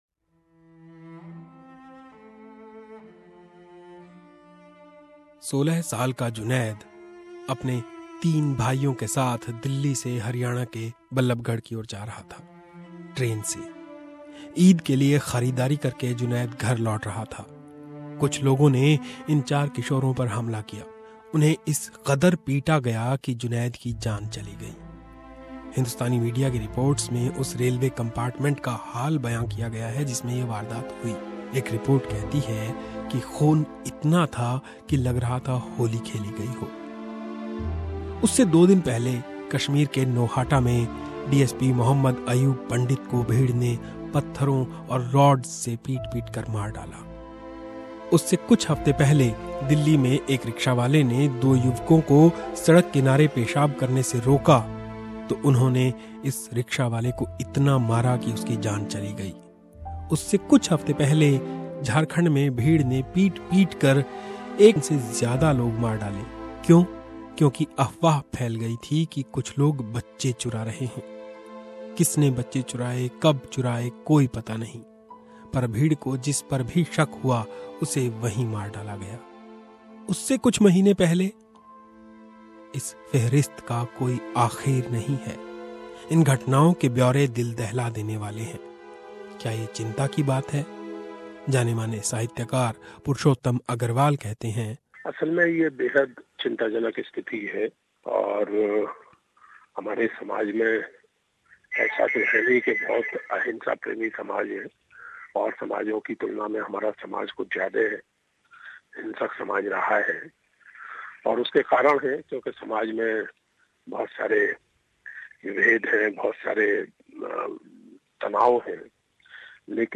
A report...